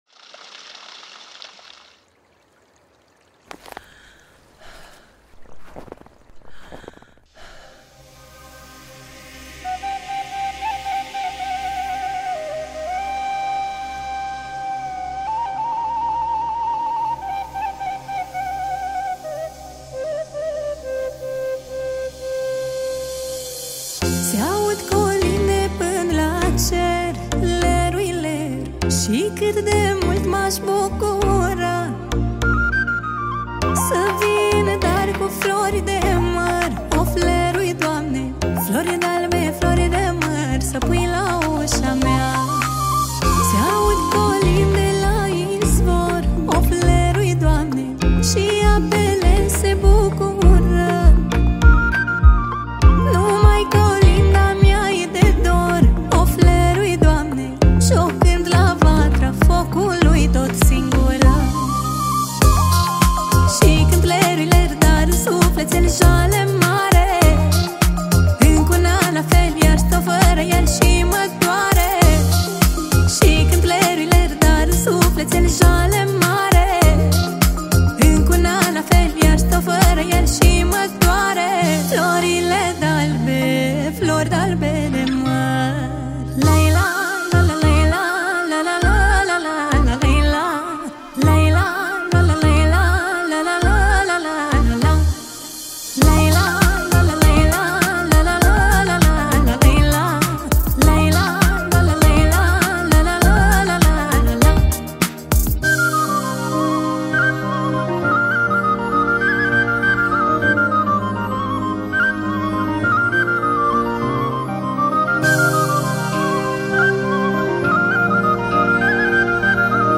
Categoria: Colinde Craciun